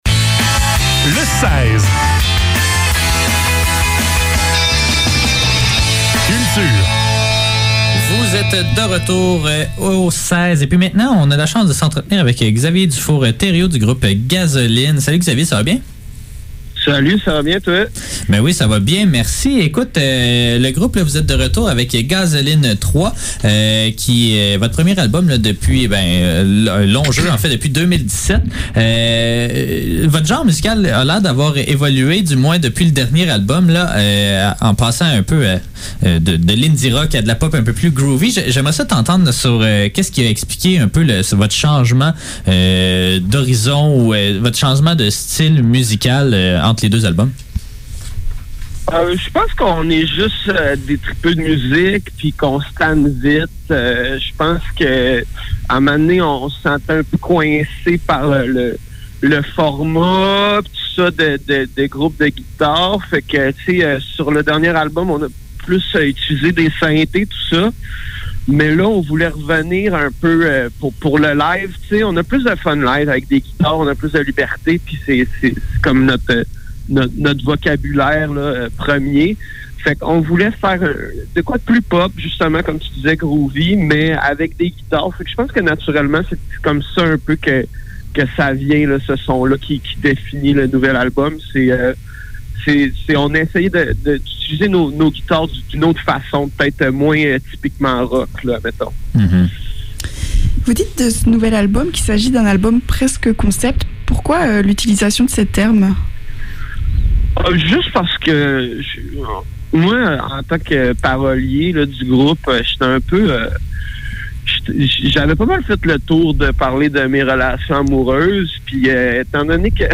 Le seize - Entrevue avec Gazoline - 11 novembre 2021